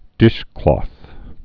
(dĭshklôth, -klŏth)